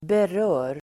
Uttal: [ber'ö:r]